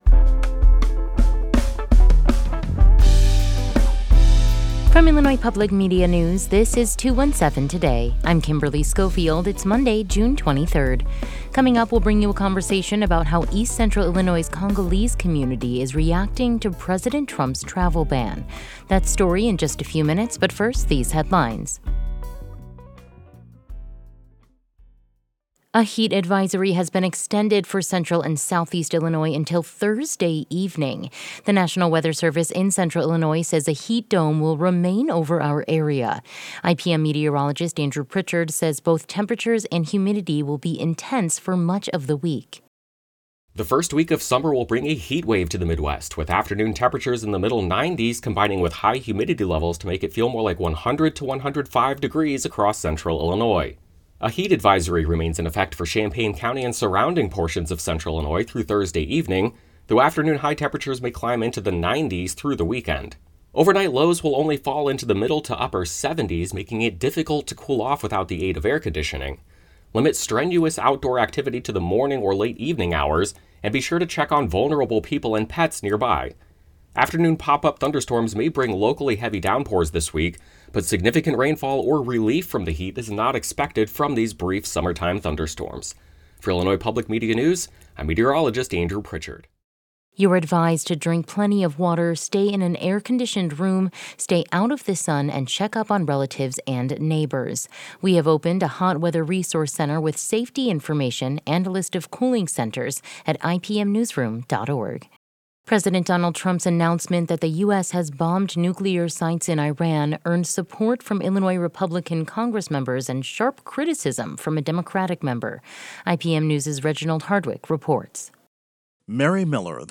In today’s deep dive, we'll bring you a conversation about how East Central Illinois' Congolese community is reacting to President Trump's travel ban.